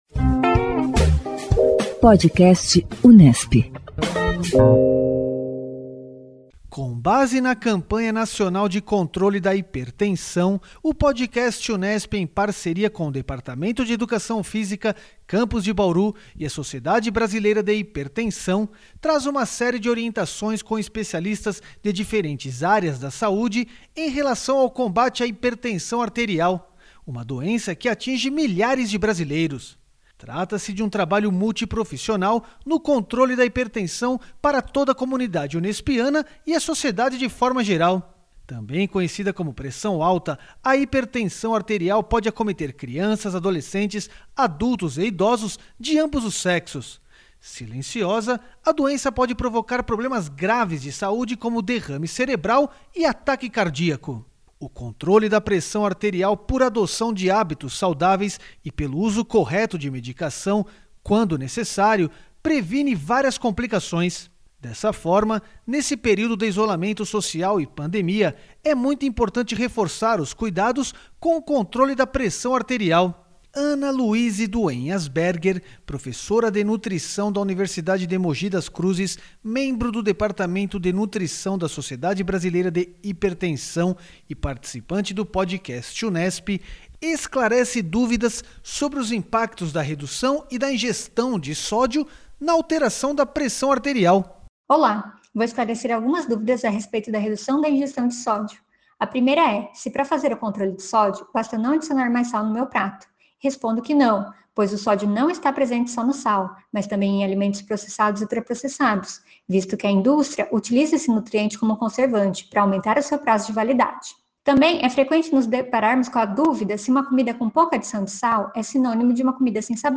[Campanha Controle da Hipertensão] Nutricionista esclarece dúvidas sobre os impactos da redução e ingestão de sódio na pressão arterial